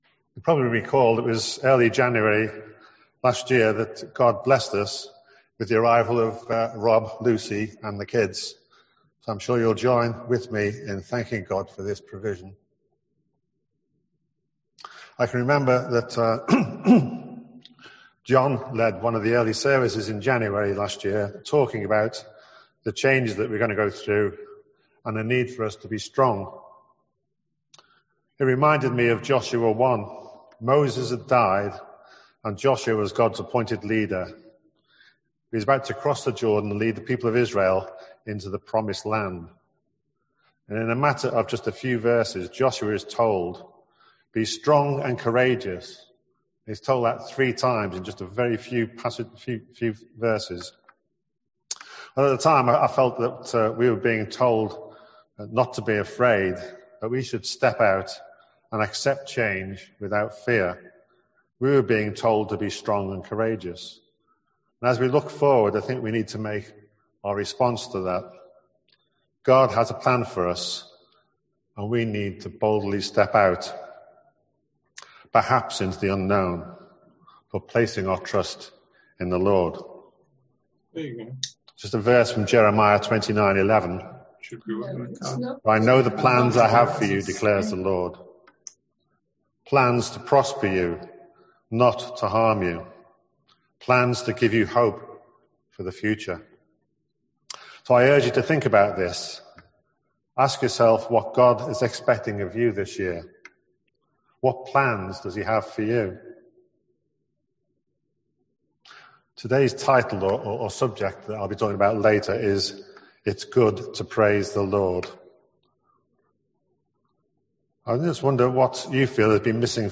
An audio file of the service is now available to listen to.